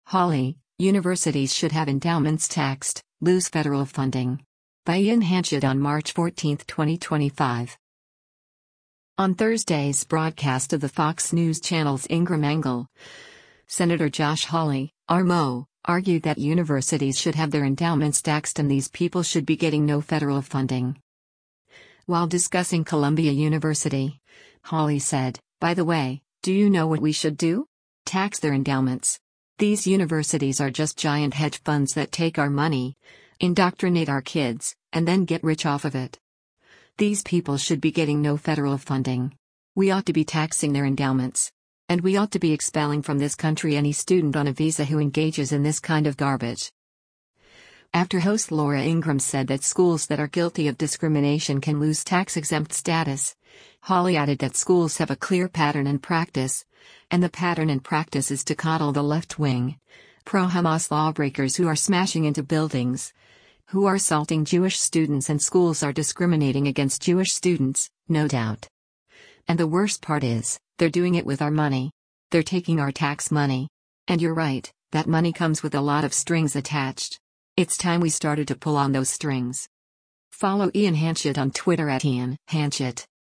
On Thursday’s broadcast of the Fox News Channel’s “Ingraham Angle,” Sen. Josh Hawley (R-MO) argued that universities should have their endowments taxed and “These people should be getting no federal funding.”